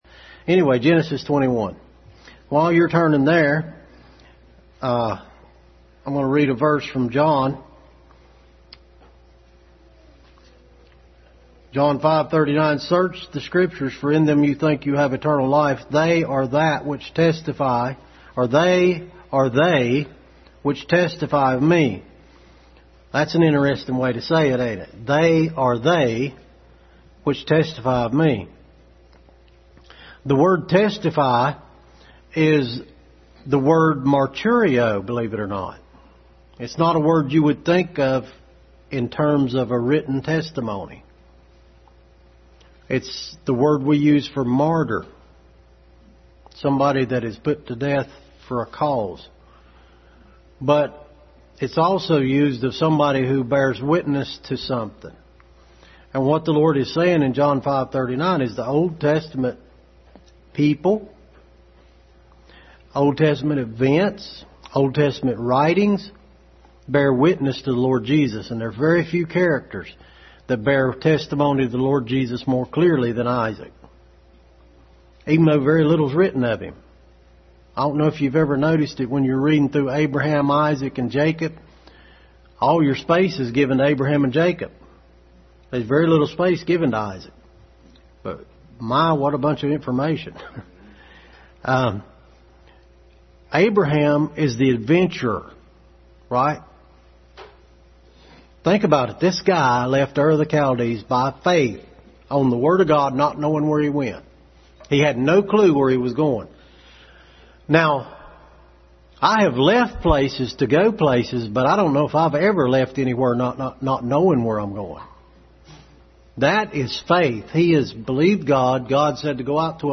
Isaac Passage: Genesis 21, John 5:39, Hebrews 11:8-10, 19 Service Type: Family Bible Hour